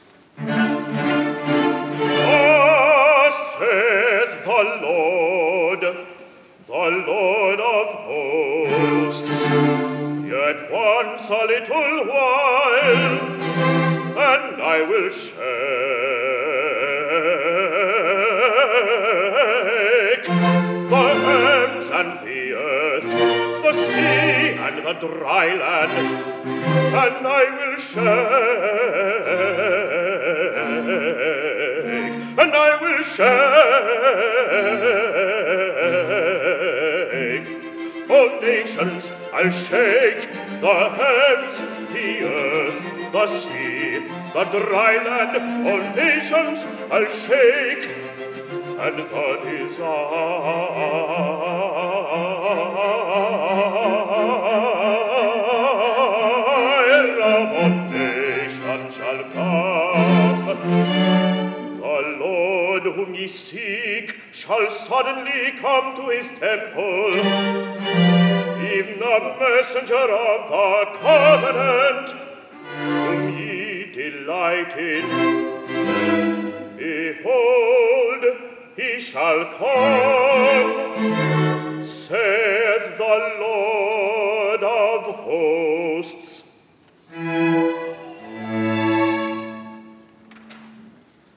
December 13, 2015 Concert
Baritone